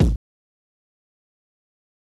Kick (Last Call).wav